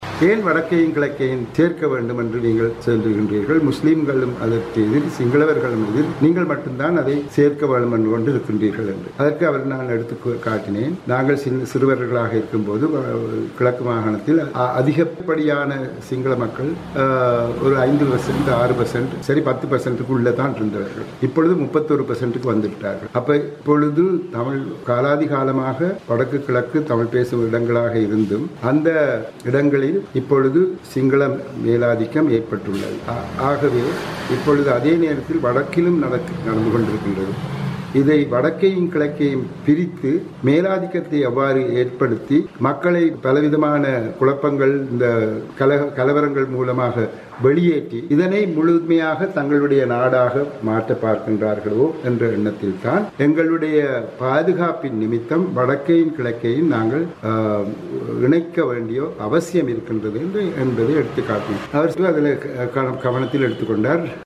இந்தச் சந்திப்பு குறித்து வடக்கு முதலமைச்சர் இவ்வாறு கருத்து வெளியிட்டார்.